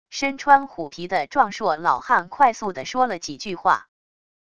身穿虎皮的壮硕老汉快速的说了几句话wav音频